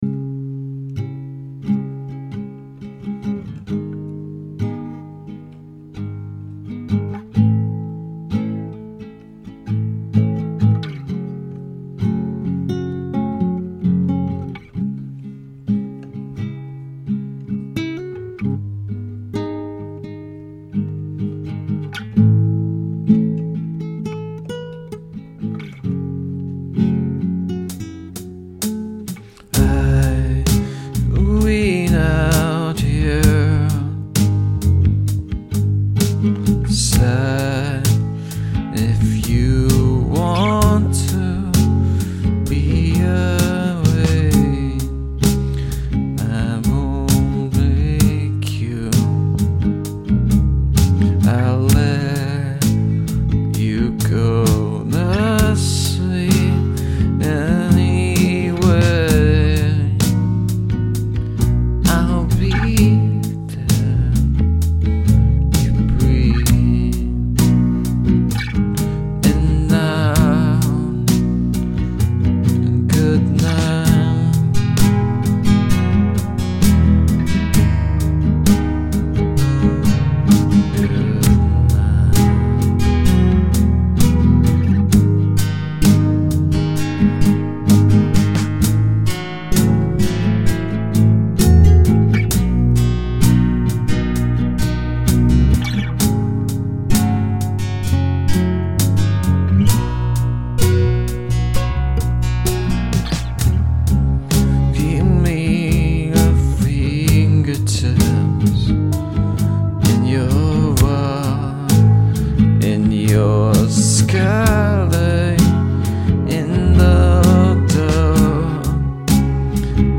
Save for the lack of cohesive lyrics within the improvised vocals, I'm fairly happy with how it turned out.
The harpsichord sounding patch that comes in during the "chorus" bits is a patch from the new Collision instrument. The song was mostly written using that patch after a rudimentary 909 beat was made. I recorded the vocals, then a patch from an FM8 synth, then the acoustic guitar, then the bass.
Very cool when the vocals come in and you have a bit of tremolo in your voice.
The voice is well recorded, I like the close sound.
The octave-guitar thing at the chorus makes it pretty.